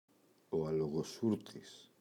αλογοσούρτης, ο [aloγoꞋsurtis] – ΔΠΗ